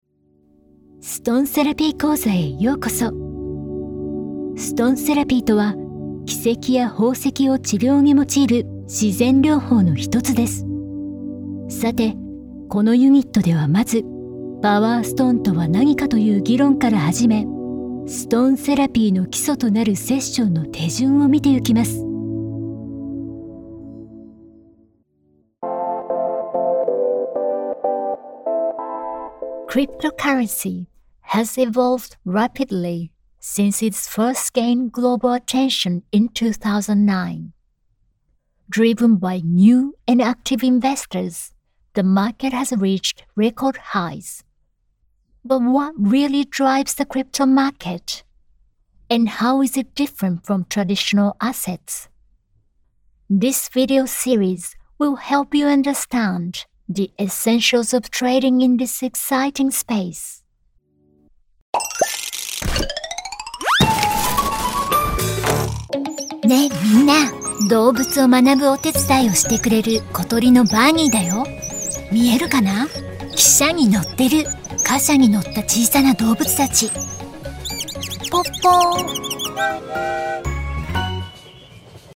E-learning – Japanese & English | Clear, Friendly & Educational
Neutral international or Japanese-accented English
• Neumann TLM 103 condenser microphone